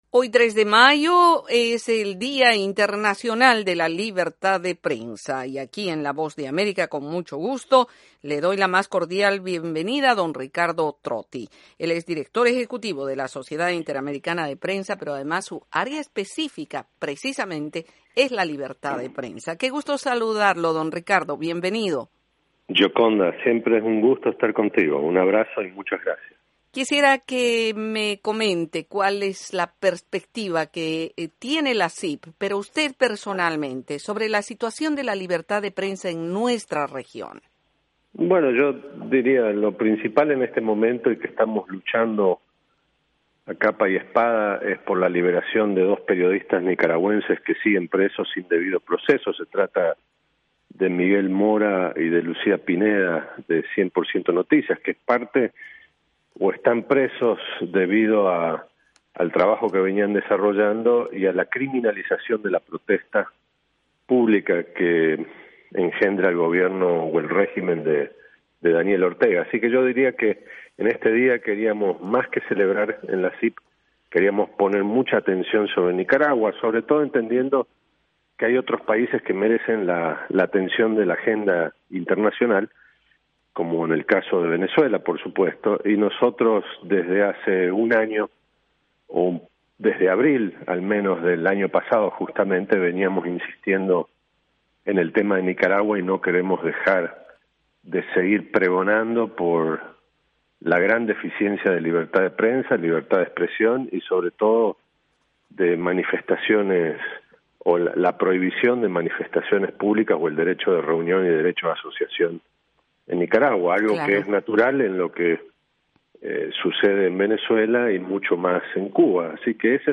en entrevista con la Voz de América y en el que ejemplifica la situación de los periodistas Miguel Mora y Lucia Pineda en Nicaragua.